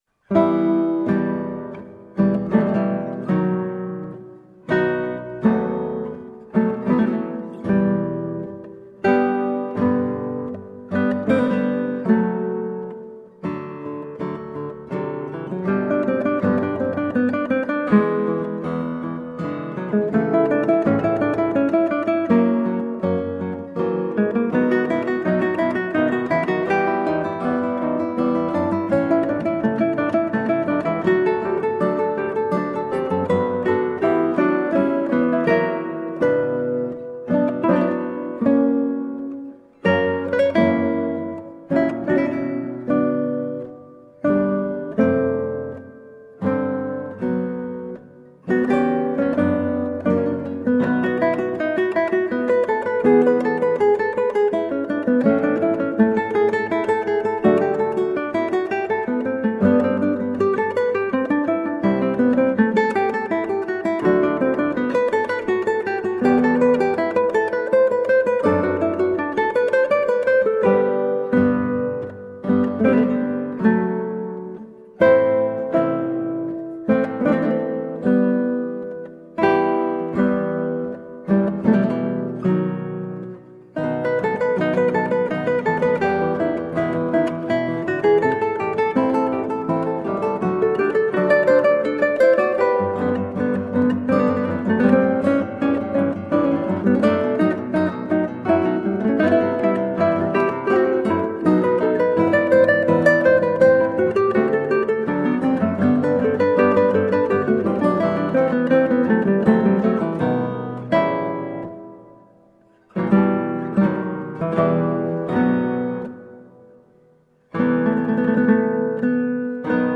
Colorful classical guitar.
Classical, Baroque, Instrumental